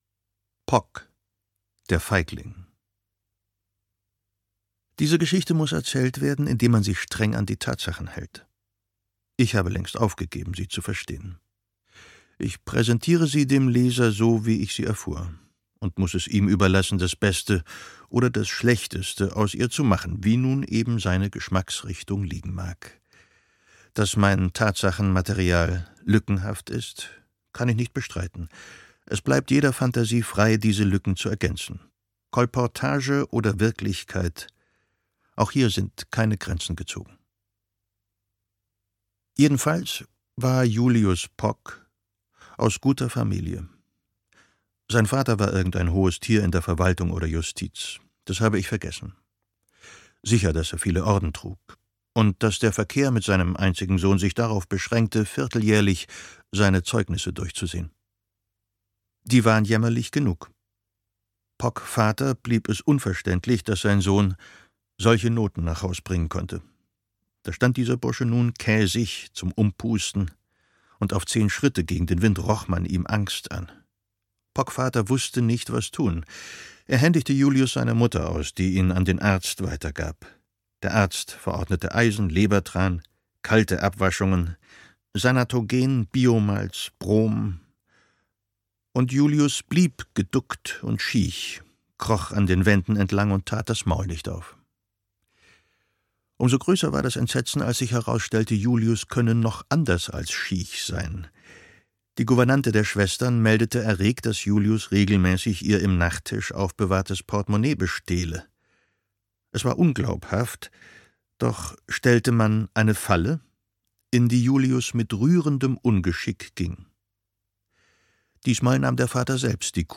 Junge Liebe zwischen Trümmern - Hans Fallada - Hörbuch